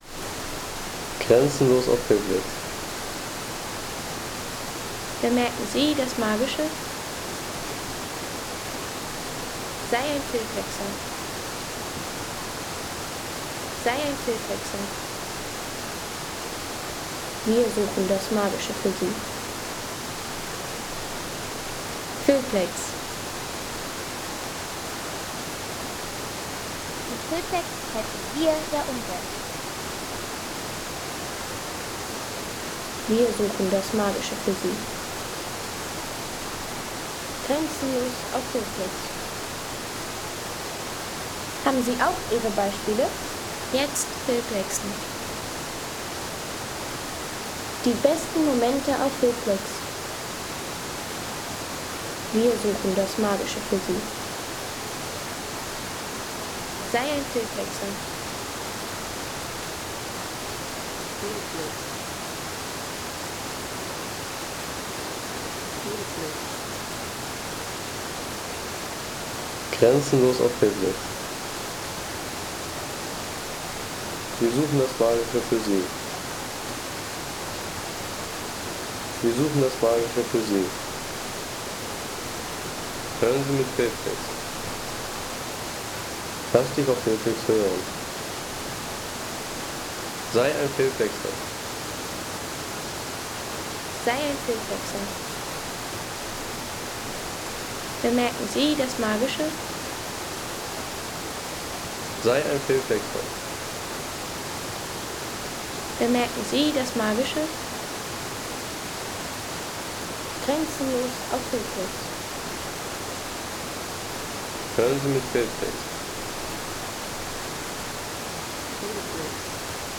Sanftes Rauschen eines kleinen Wasserfalls im Nationalpark Gesäuse – aufgenommen vom hölzernen Steig auf dem Weg zum Palfauer Wasserloch.